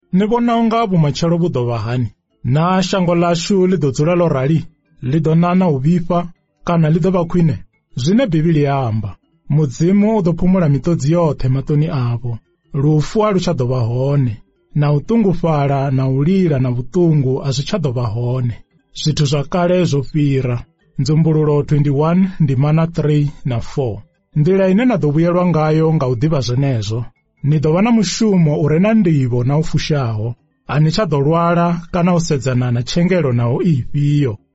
Apparently I can’t detect more than two tones, high and low. I have seemed to hear some very soft ejective sounds and above all what seems to my ears-not for the speakers- to be confusion between r (one flap), rr (some flaps) and l ; which for me is typical of the Venda language.